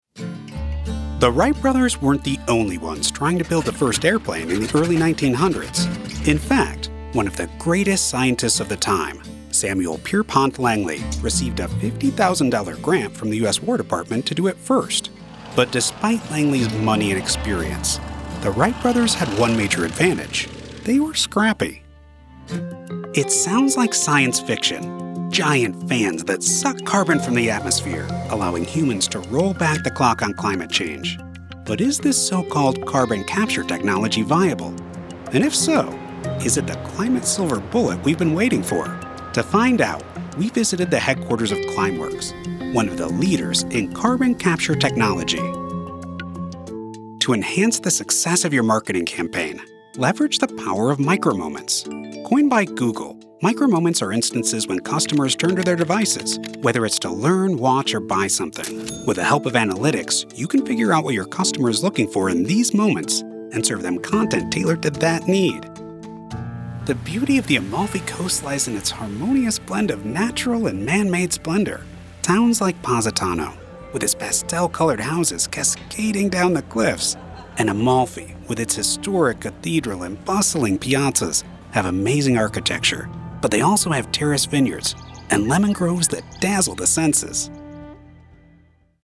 The warm, confident, intelligent voice of the guy next door
Midwestern / Neutral
Middle Aged